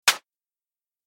دانلود آهنگ باد 75 از افکت صوتی طبیعت و محیط
دانلود صدای باد 75 از ساعد نیوز با لینک مستقیم و کیفیت بالا
جلوه های صوتی